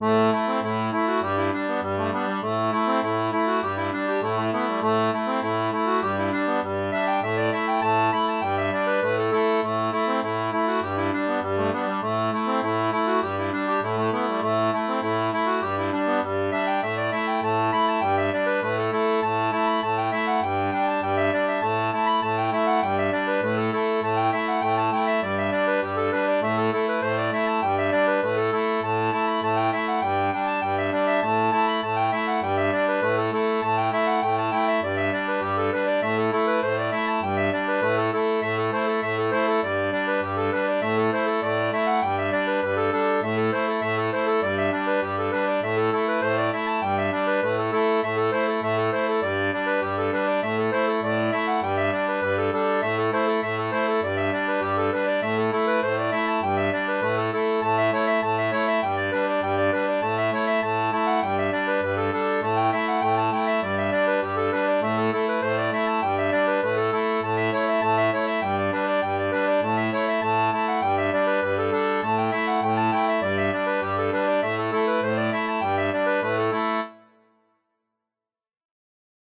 • une version pour accordéon diatonique à 2 rangs
Folk